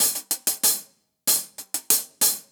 Index of /musicradar/ultimate-hihat-samples/95bpm
UHH_AcoustiHatC_95-01.wav